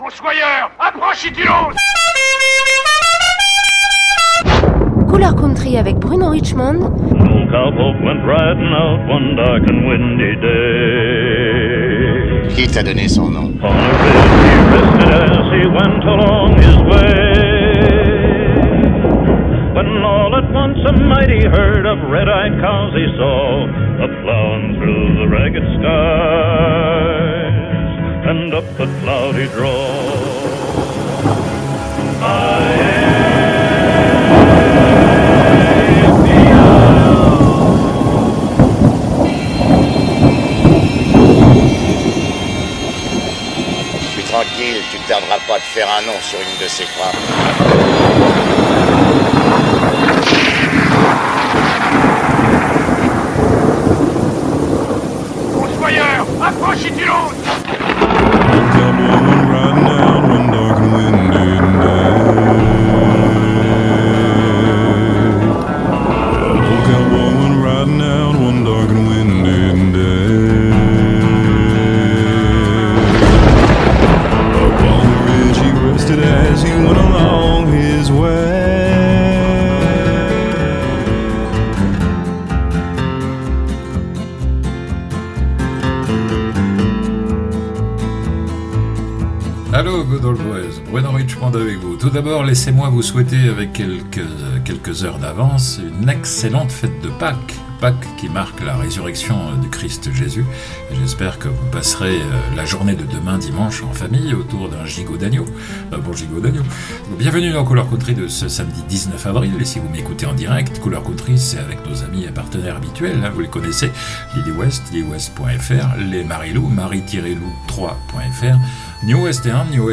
Durant une heure je vais vous offrir le meilleur de la Country, mon Top Ten, le classement de mes dix chansons country préférées.